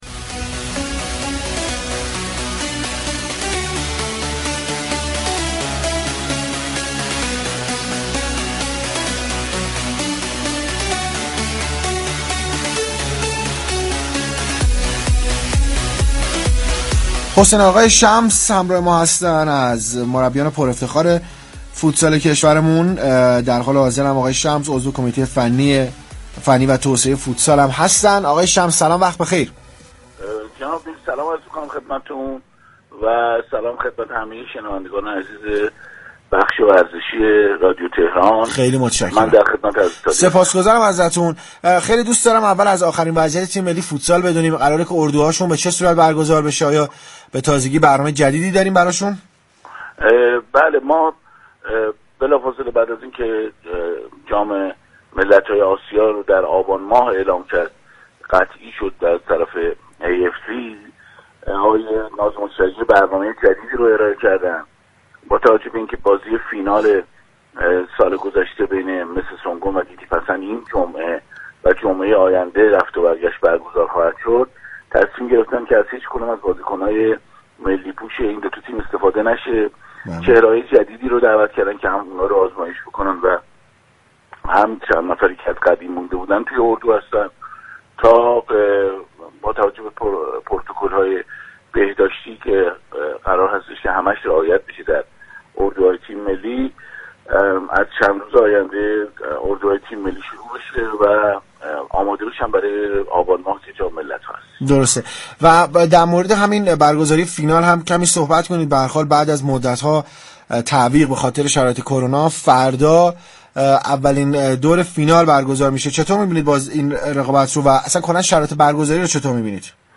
در گفتگو با تهران ورزشی